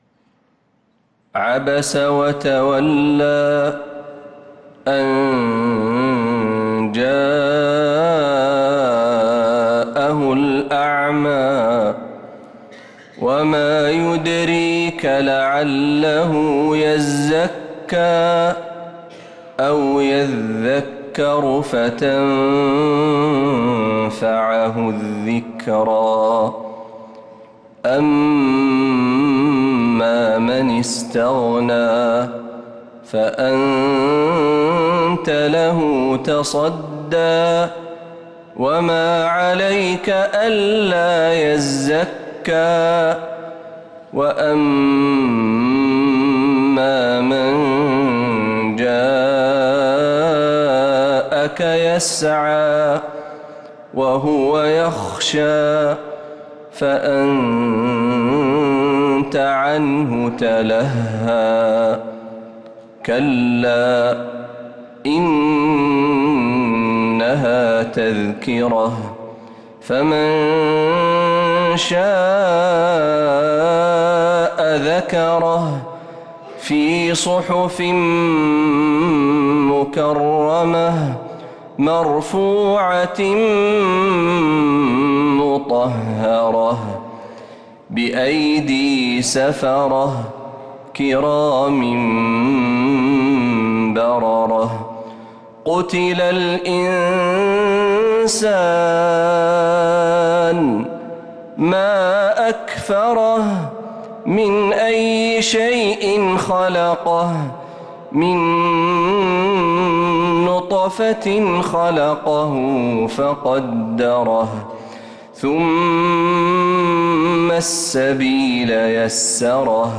سورة عبس كاملة من عشائيات الحرم النبوي للشيخ محمد برهجي | جمادى الأولى 1446هـ > السور المكتملة للشيخ محمد برهجي من الحرم النبوي 🕌 > السور المكتملة 🕌 > المزيد - تلاوات الحرمين